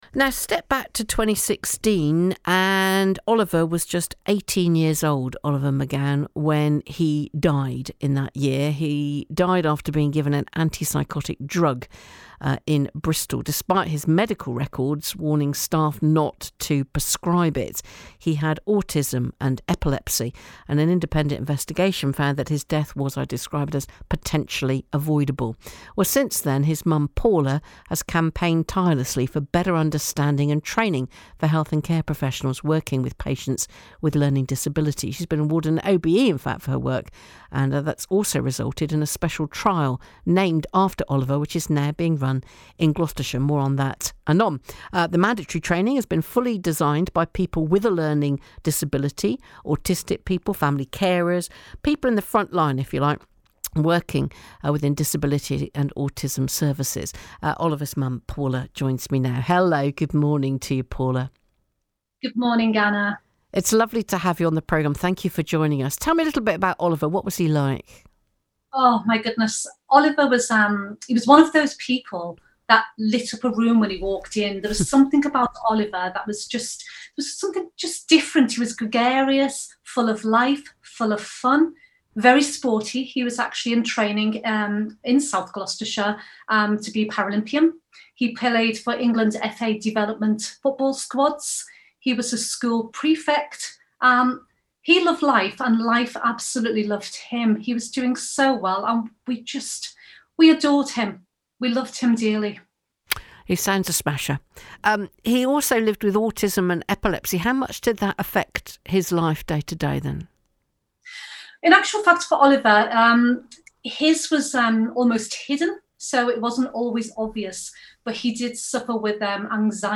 BBC Gloucester interview